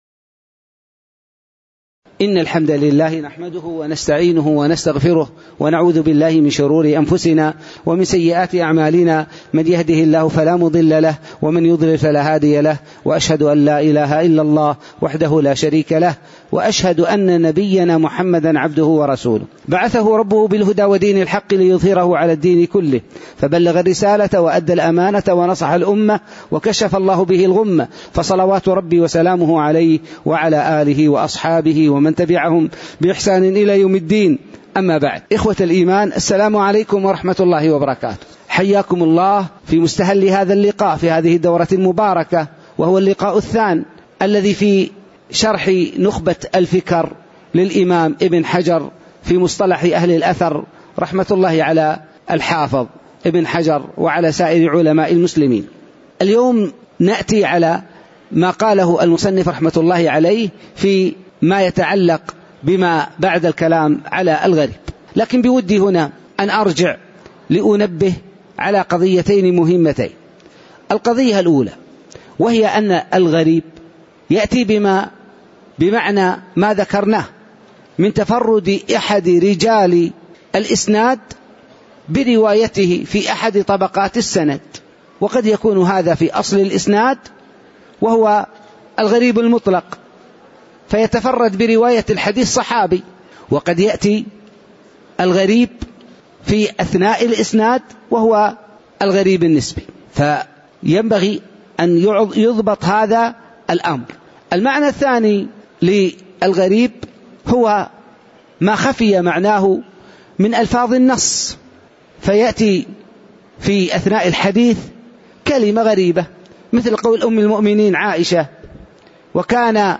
تاريخ النشر ١٢ شوال ١٤٣٩ هـ المكان: المسجد النبوي الشيخ